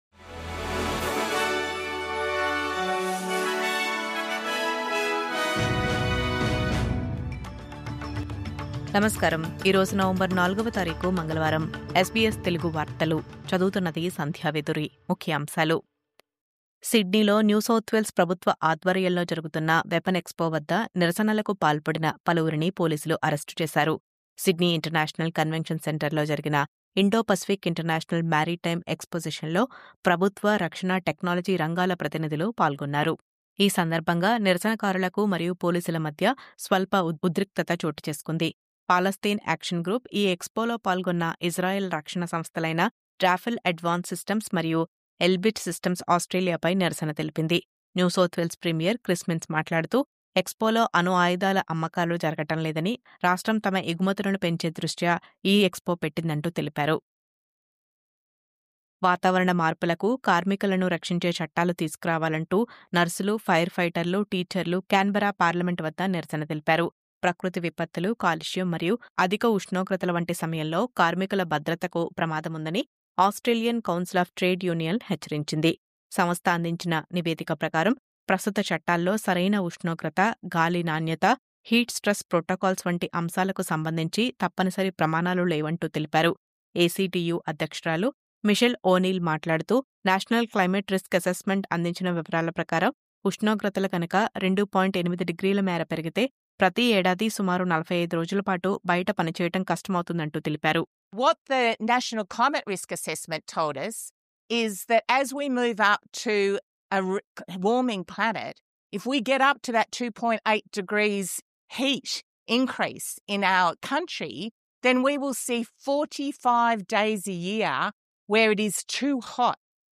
News Update: అదే వడ్డీ రేటును కొనసాగిస్తున్న RBA, కార్మికుల భద్రత కోసం చట్టాల డిమాండ్, వెపన్ ఎక్స్‌పోలో నిరసనలు..